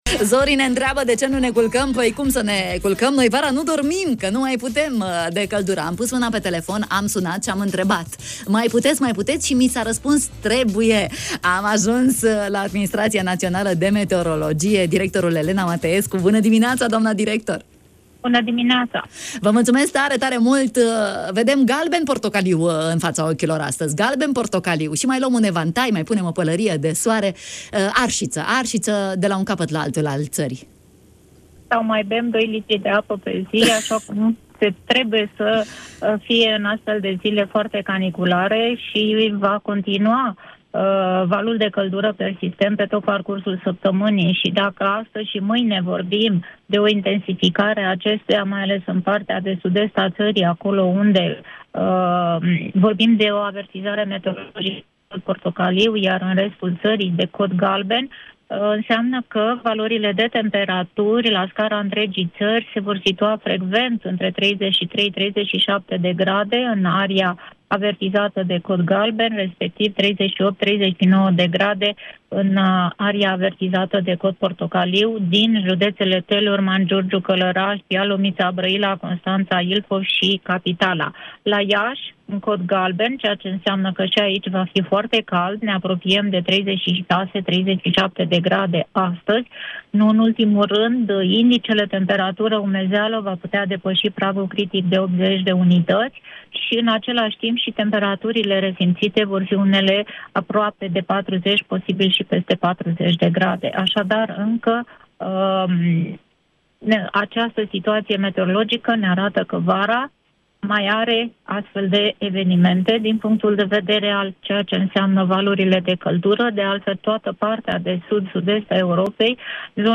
Zile caniculare și harta țării colorată de temperaturi ridicate. Elena Mateescu, director la Adminsitrația Națională de Meteorologie în direct în matinalul de la Radio România Iași: